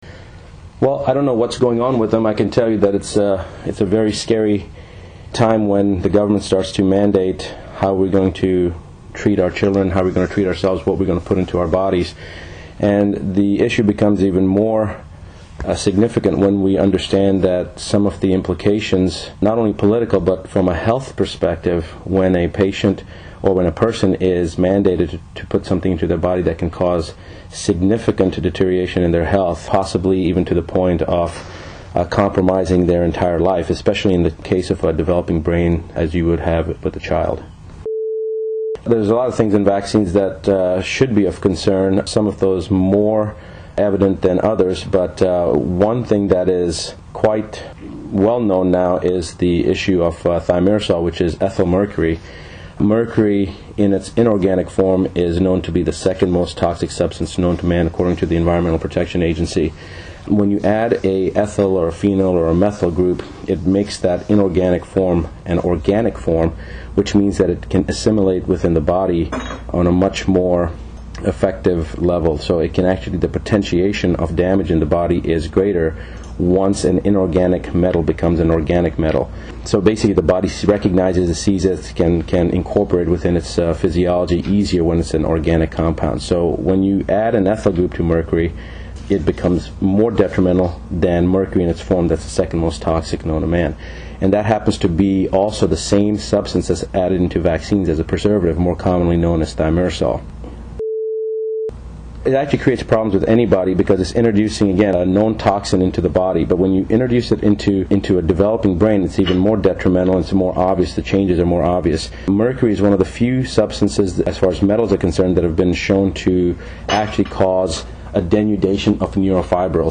Each week, you will receive approximately a 14 to 20 minute long segment of one of these lectures that was previously presented in front of hundreds of people.
These attendees travelled to live conferences, where these lectures were presented and attended these lectures at a substantial cost.